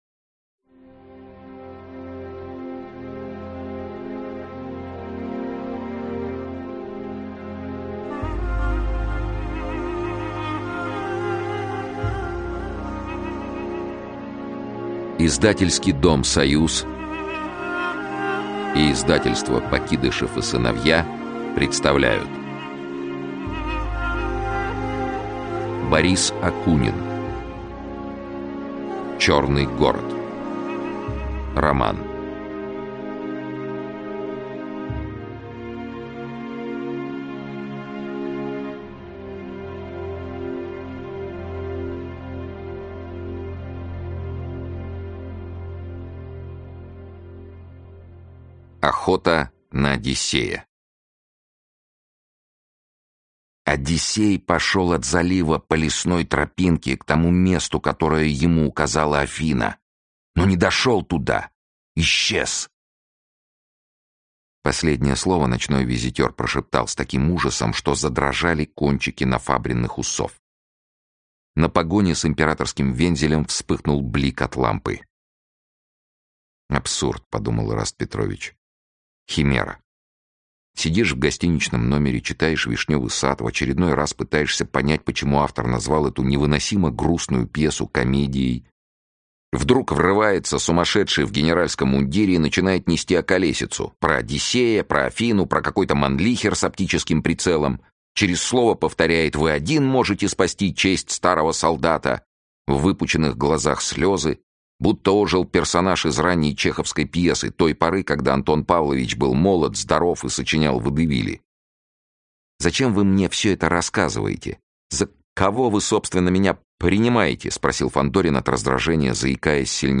Аудиокнига Чёрный город - купить, скачать и слушать онлайн | КнигоПоиск